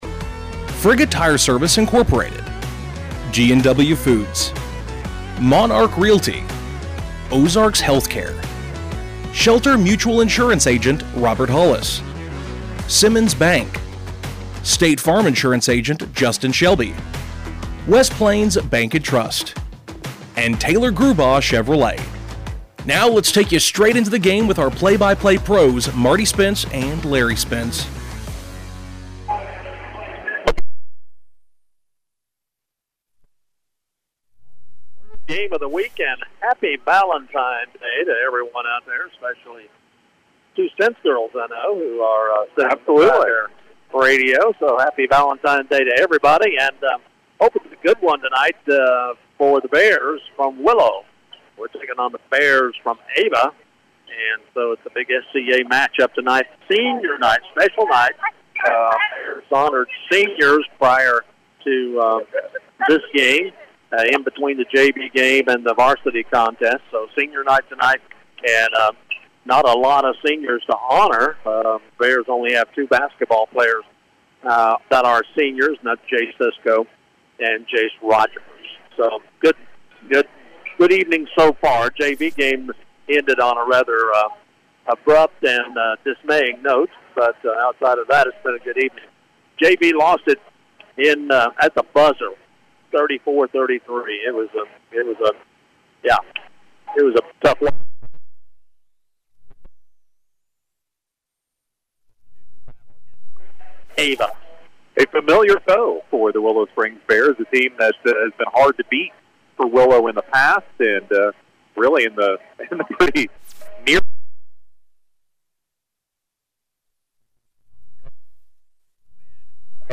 Game Audio Below: